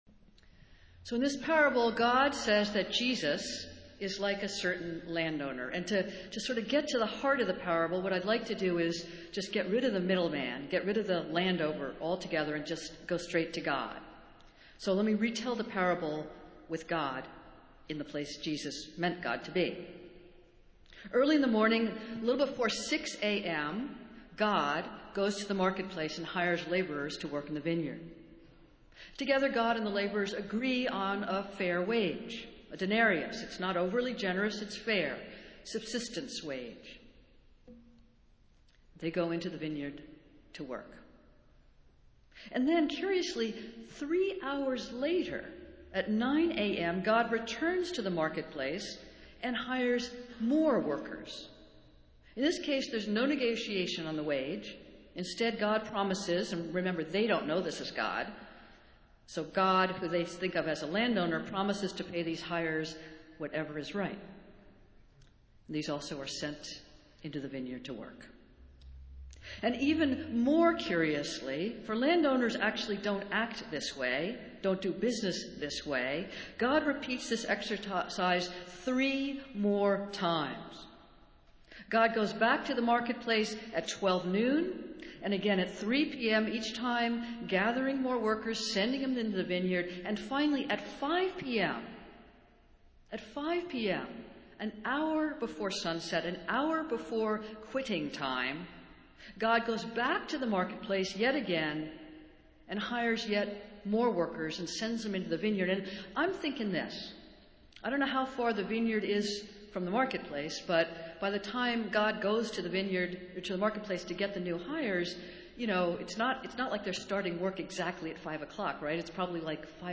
Festival Worship - Fifteenth Sunday after Pentecost